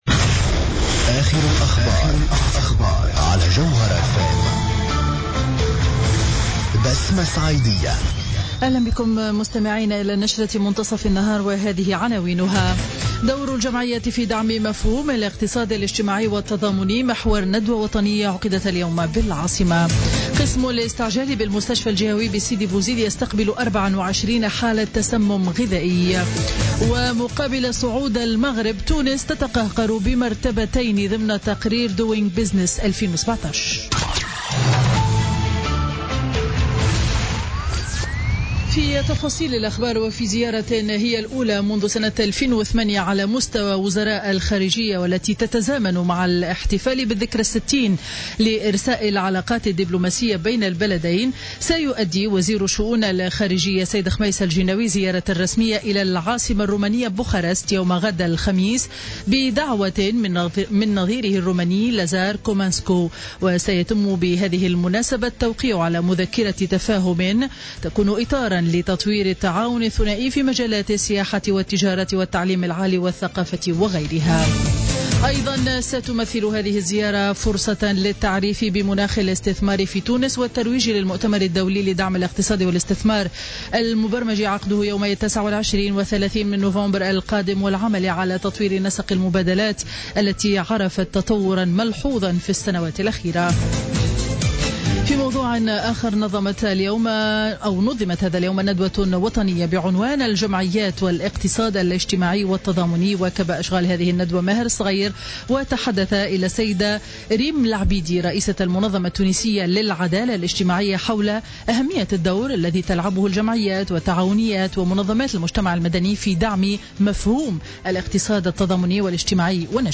Journal Info 12h00 du mercredi 26 octobre 2016